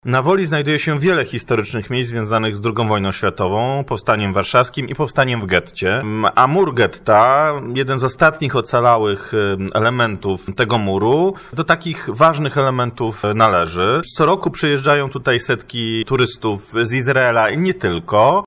– Jest to kolejne już działanie mające na celu nadanie temu miejscu należnego mu wyglądu – mówi Krzysztof Strzałkowski, burmistrz Dzielnicy Wola.